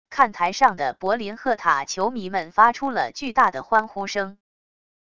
看台上的柏林赫塔球迷们发出了巨大的欢呼声wav音频